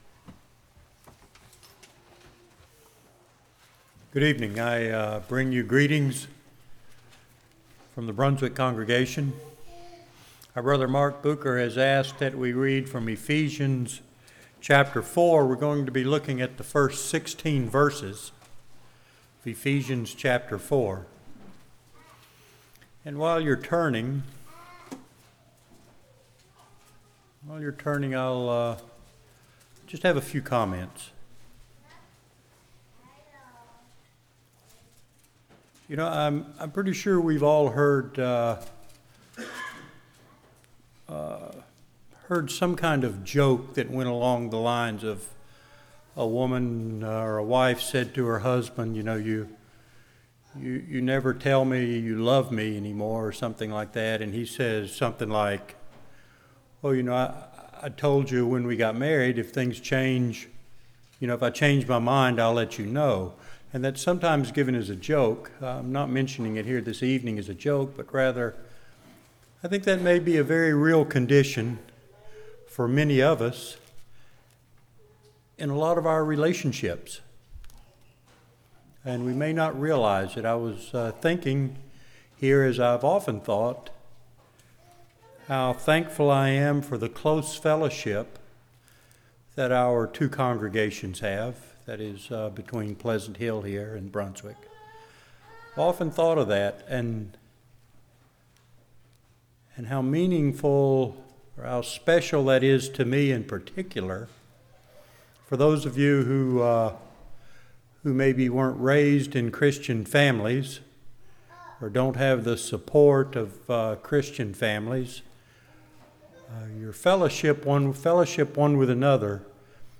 Service Type: Commissioning Service